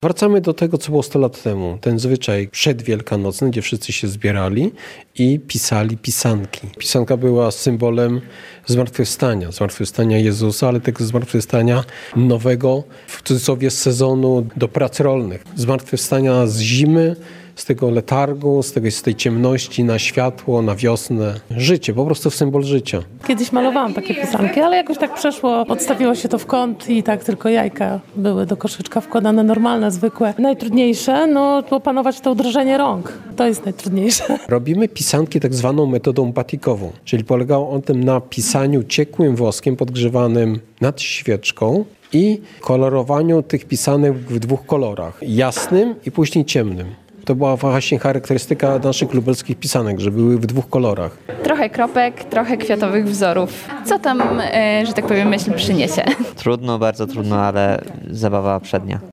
Tradycja malowania pisanek wciąż jest żywa. A w sobotę, 21 marca w Muzeum Wsi Lubelskiej odbyły się warsztaty ich malowania.